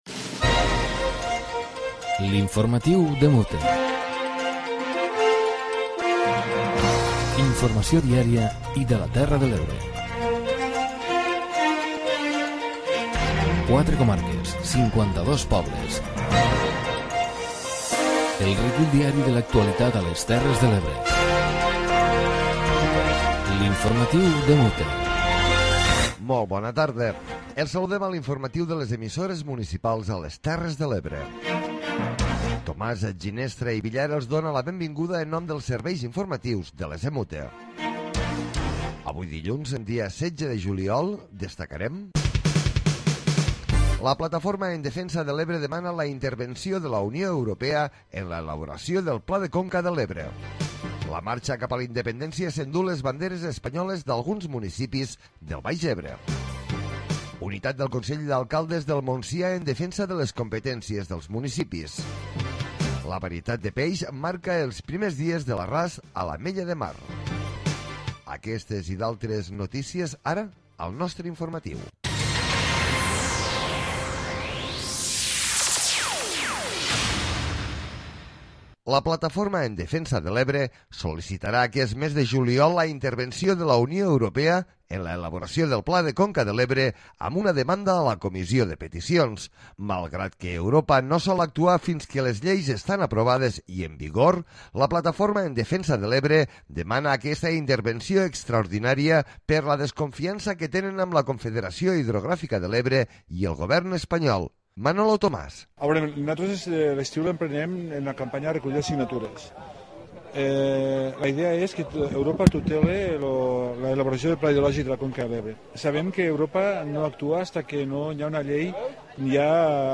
Informatiu comarcal diari de les emissores municipals de les Terres de l'Ebre.